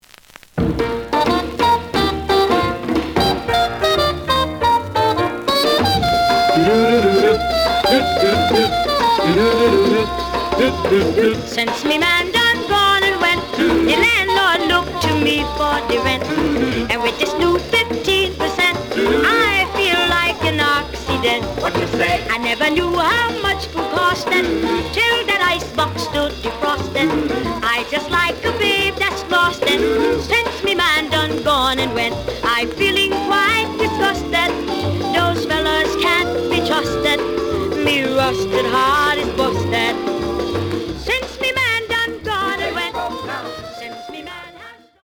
試聴は実際のレコードから録音しています。
●Format: 7 inch
●Genre: Rhythm And Blues / Rock 'n' Roll